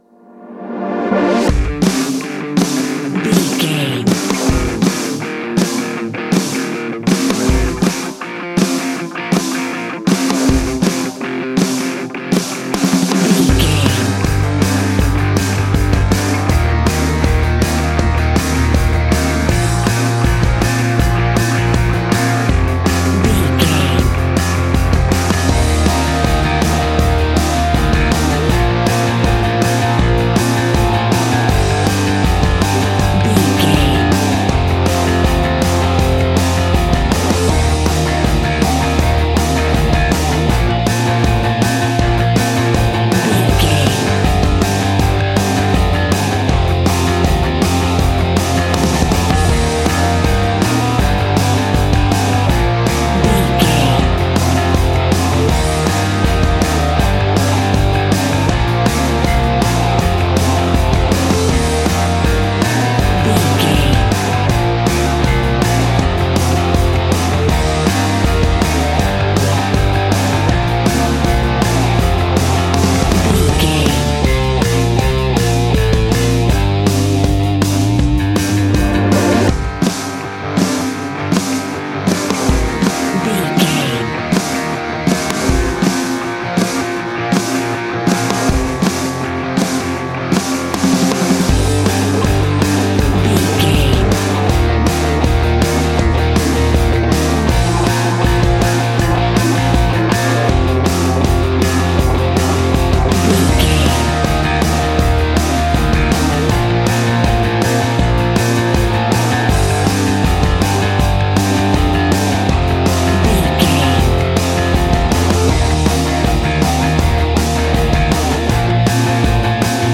Aeolian/Minor
groovy
powerful
electric organ
electric guitar
bass guitar